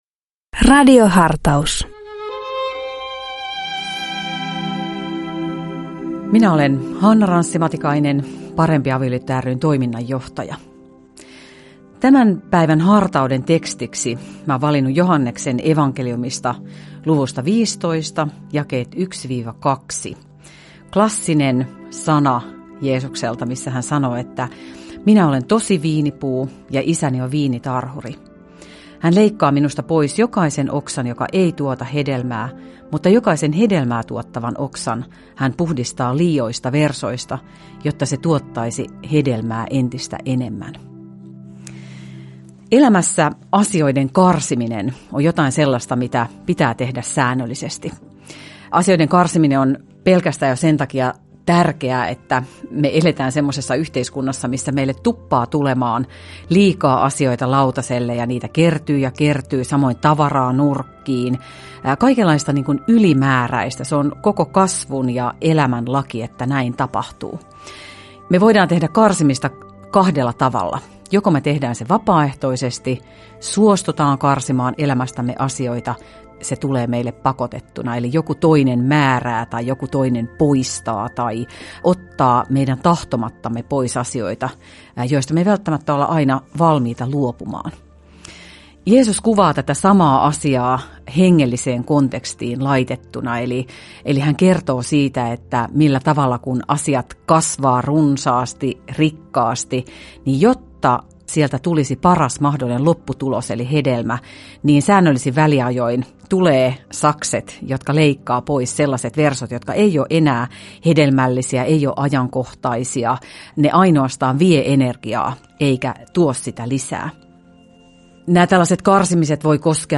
Radio Dei lähettää FM-taajuuksillaan radiohartauden joka arkiaamu kello 7.50. Radiohartaus kuullaan uusintana iltapäivällä kello 16.50. Radio Dein radiohartauksien pitäjinä kuullaan laajaa kirjoa kirkon työntekijöitä sekä maallikoita, jotka tuntevat radioilmaisun omakseen.